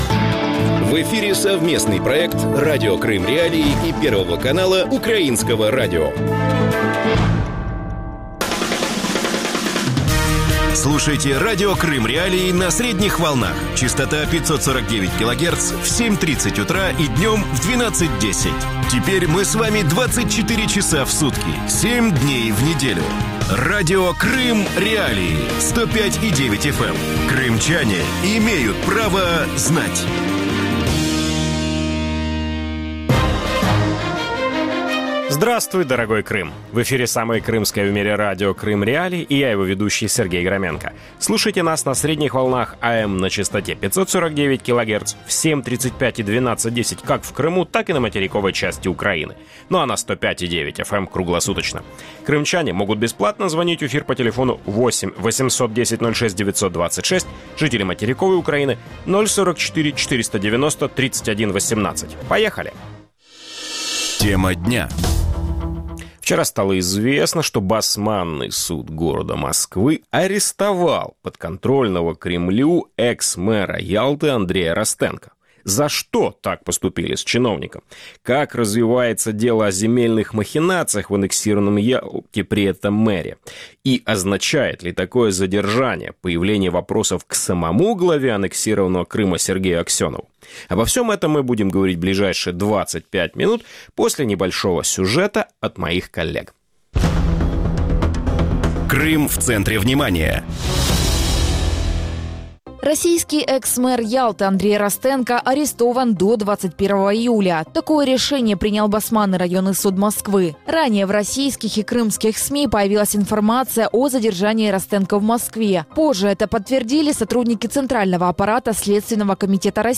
украинский политолог, политтехнолог, эксперт по международным отношениям.